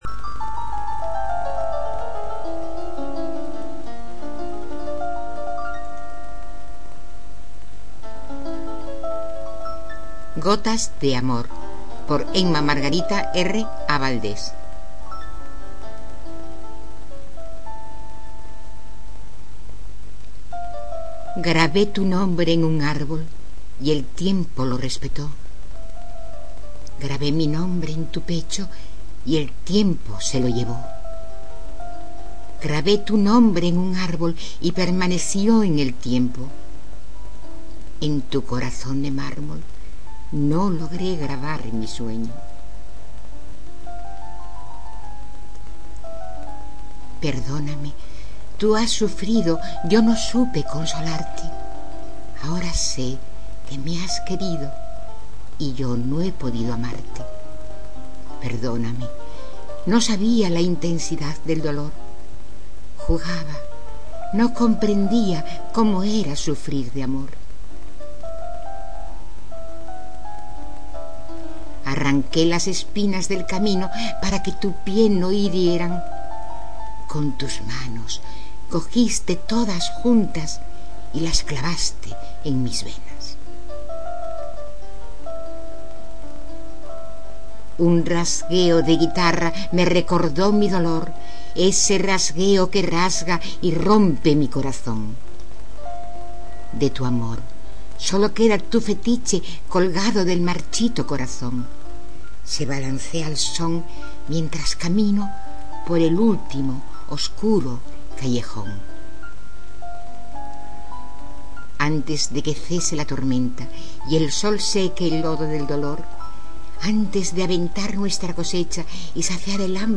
En mp3, recitada por la autora.